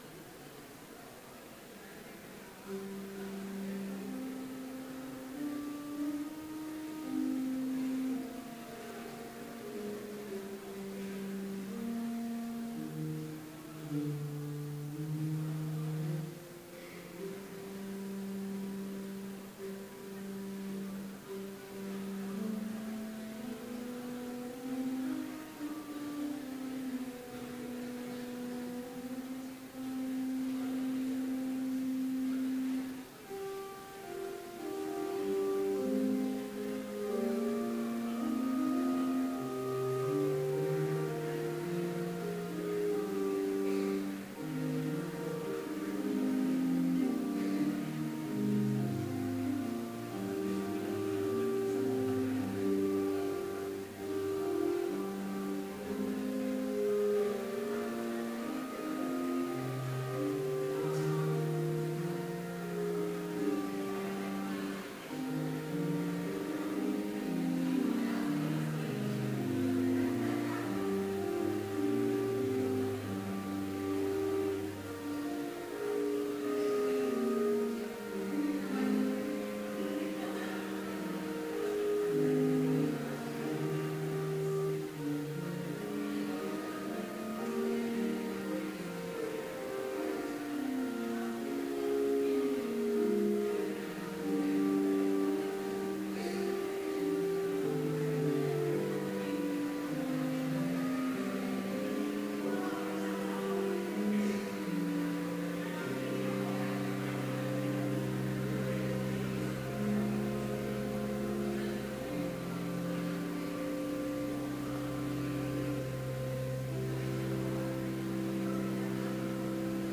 Complete service audio for Chapel - January 12, 2016
Hymn 178, I Pray Thee, Dear Lord Jesus